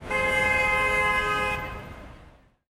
Descarga de Sonidos mp3 Gratis: bocina 13.